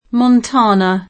vai all'elenco alfabetico delle voci ingrandisci il carattere 100% rimpicciolisci il carattere stampa invia tramite posta elettronica codividi su Facebook Montana [ mont # na ; ingl. mont # anë o montä ^ në ] top. m. (S. U.)